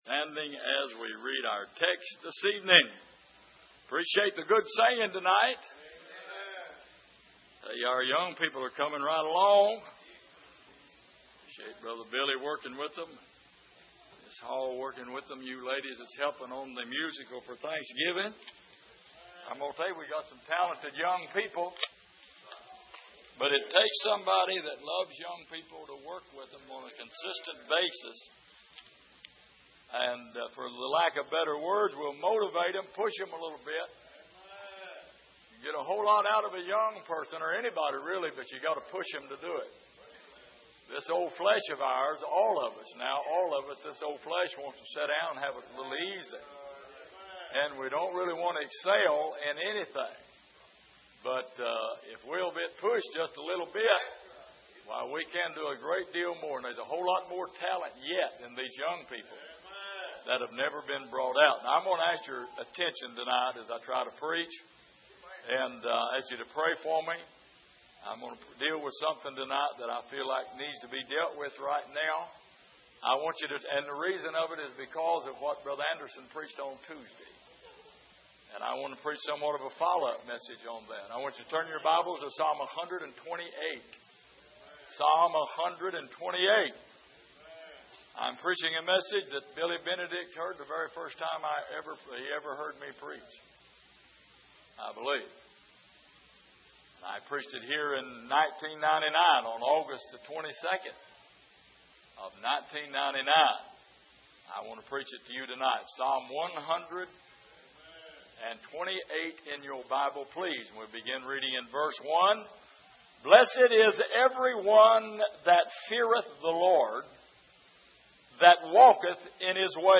Sermon Archive
Here is an archive of messages preached at the Island Ford Baptist Church.